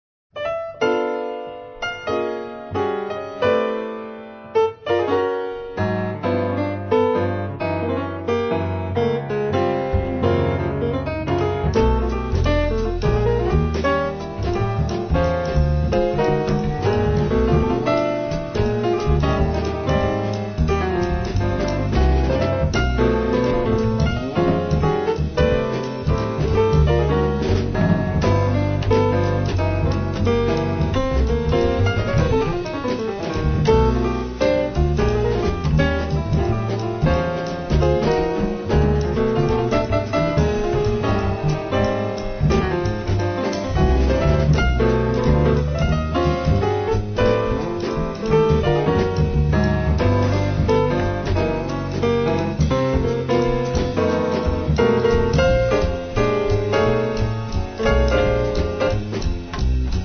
Piano
Contrabbasso
Batteria